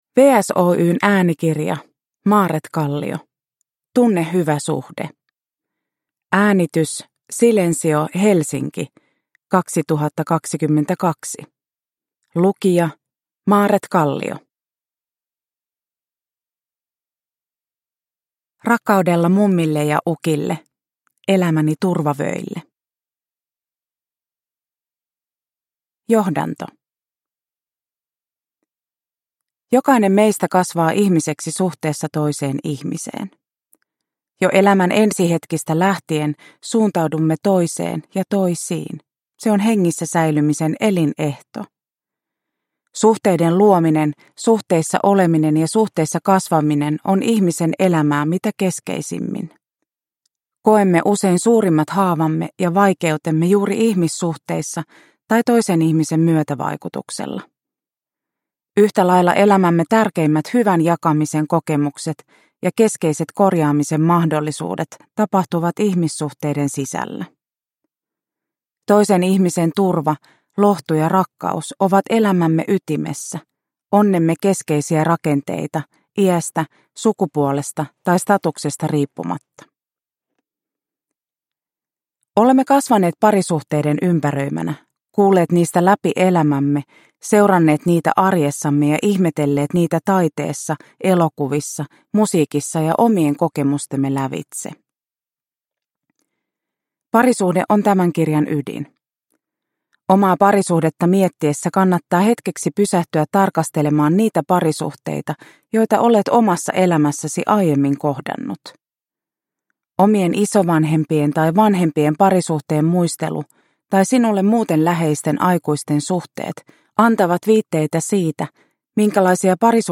Tunne hyvä suhde – Ljudbok – Laddas ner